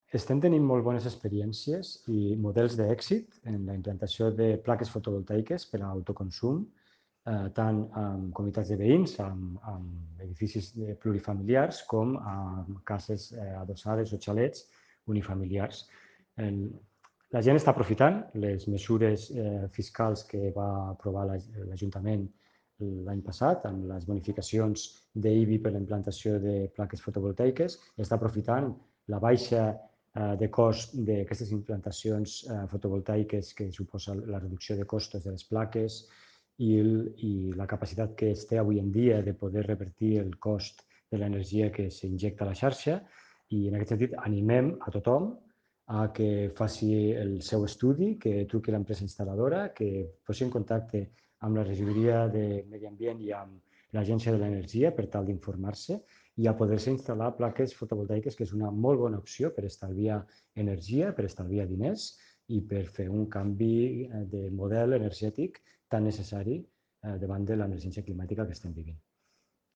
Fitxers relacionats Tall de veu de l'alcalde accidental, Sergi Talamonte, sobre les comunitats de veïns pioneres a Lleida en la instal·lació de plaques fotovoltaiques per a l’autoconsum elèctric compartit (1.1 MB)